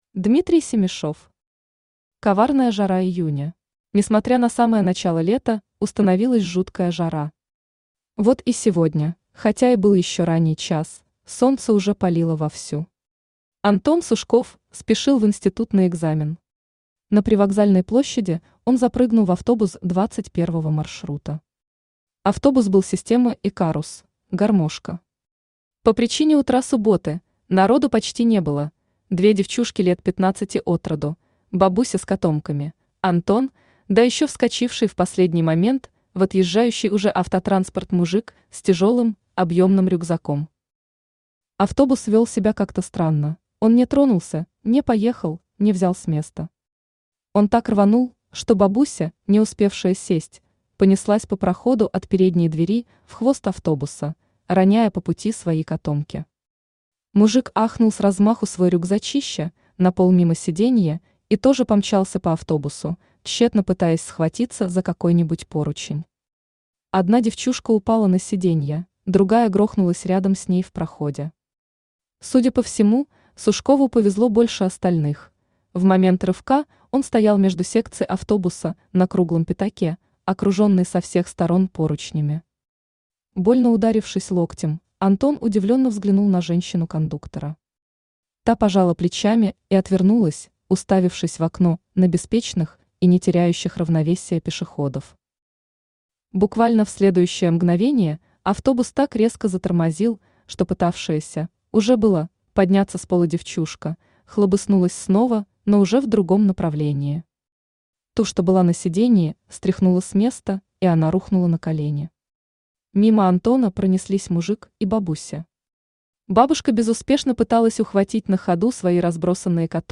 Аудиокнига Коварная жара июня | Библиотека аудиокниг
Aудиокнига Коварная жара июня Автор Дмитрий Петрович Семишев Читает аудиокнигу Авточтец ЛитРес.